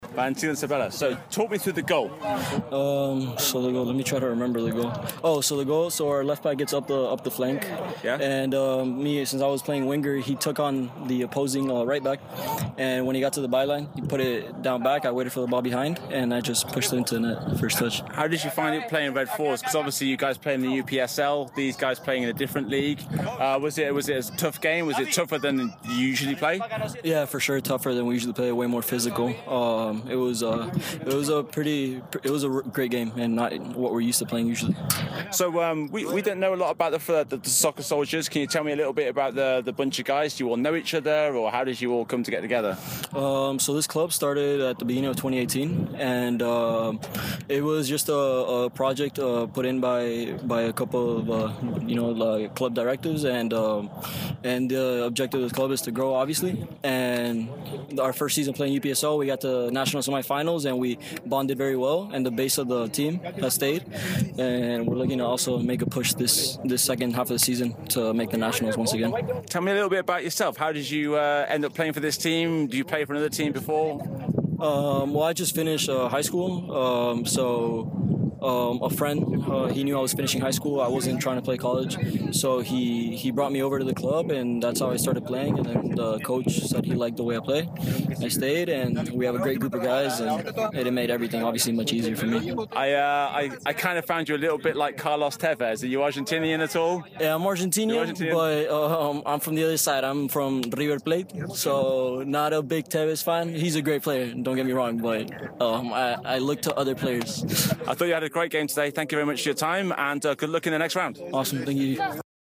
Post match interview